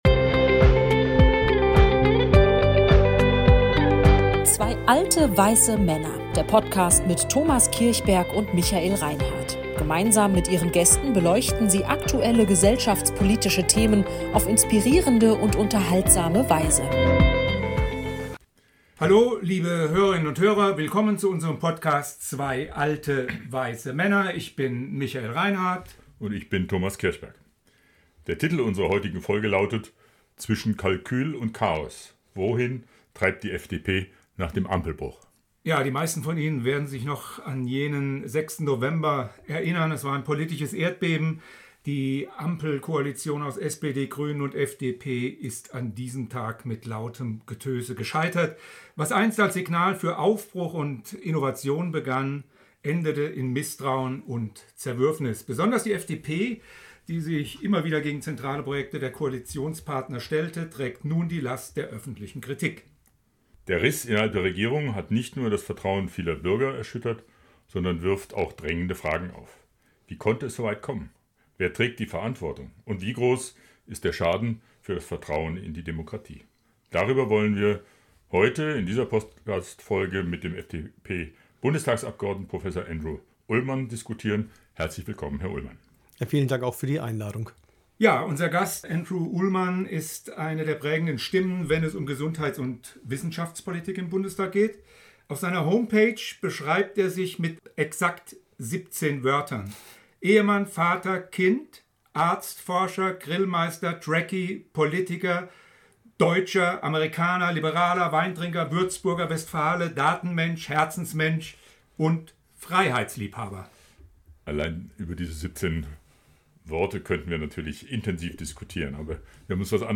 Zur Person Unser Gast Andrew Ullmann ist eine der prägenden Stimmen, wenn es um Gesundheits- und Wissenschaftspolitik im Bundestag geht.